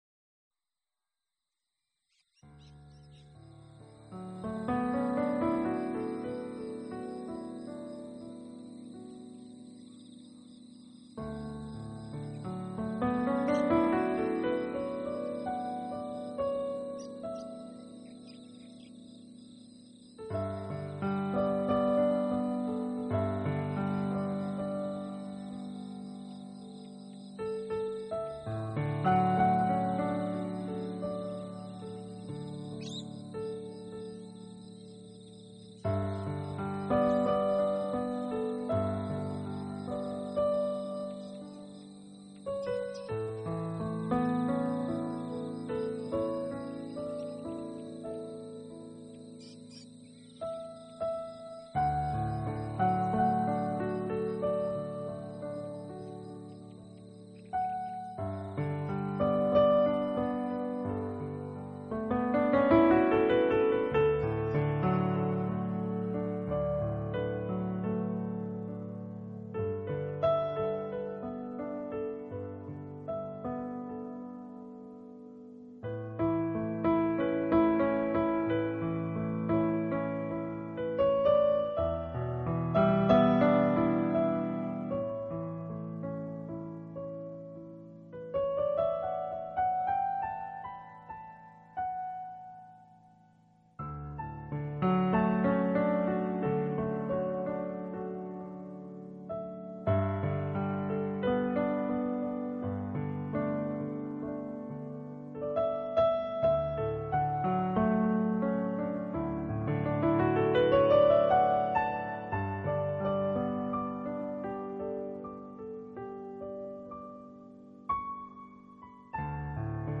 Genre..........: New Age